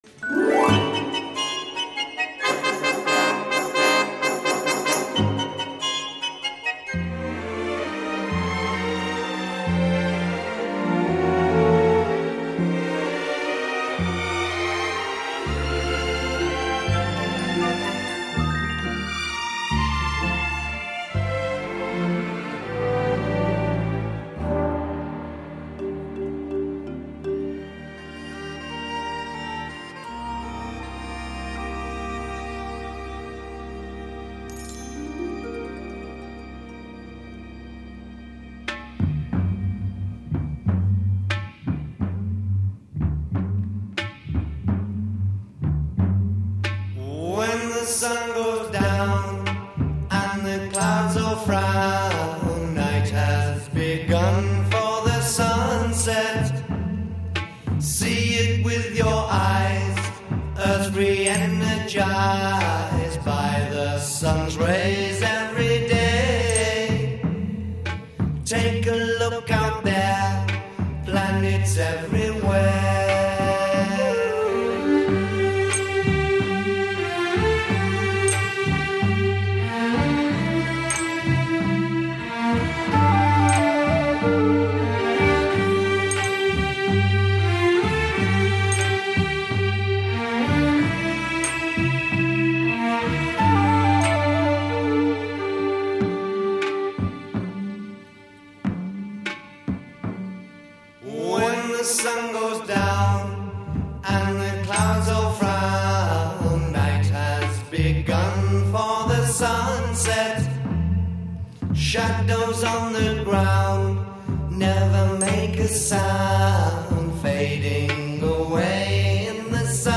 Recorded 26 October 1967 at Decca Studios, West Hampstead.
Introduction   Orchestra
Introduction   Bass and drum.
Verse   Double-tracked solo voice with rock ensemble. a
Elaboration   Mellotronn and flute.
Coda   Strings, Mellotron, bass and percussion.
Coda   Orchestra with reference to afternoon theme.